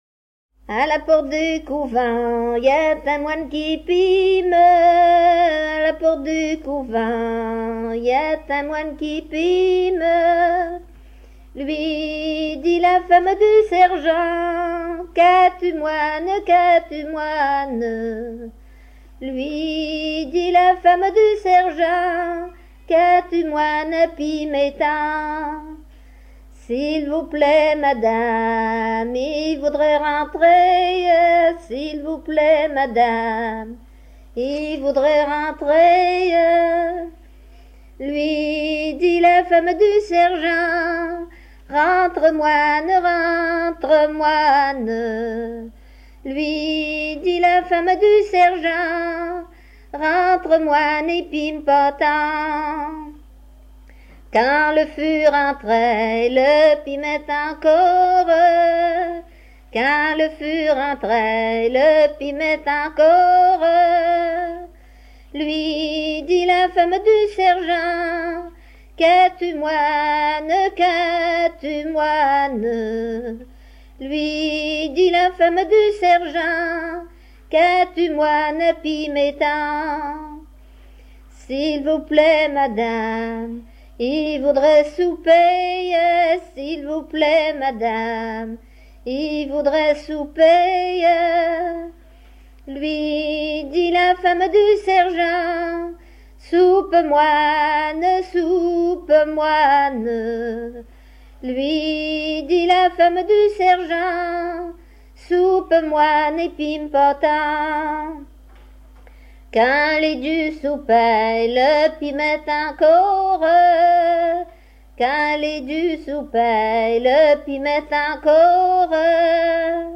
Saint-Christophe-du-Ligneron
Genre énumérative